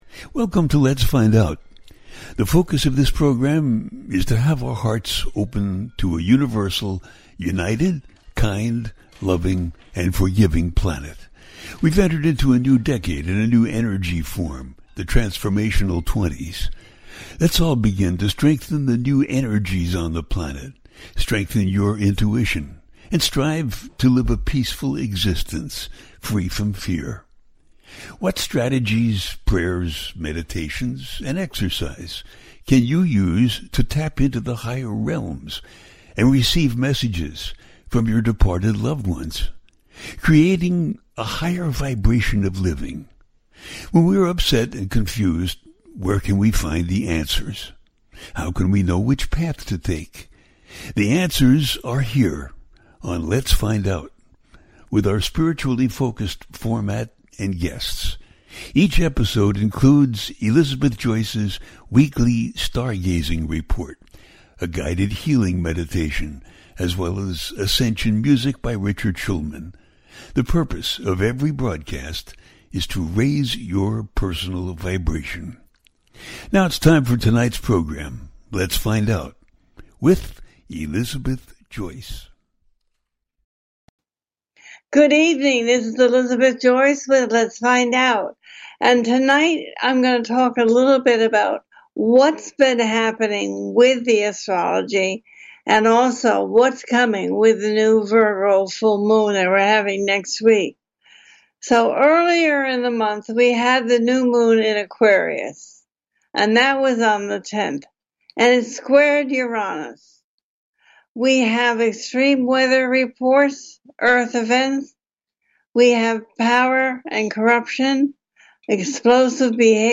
New Discoveries and the Full Moon in Virgo in February-2024, A teaching show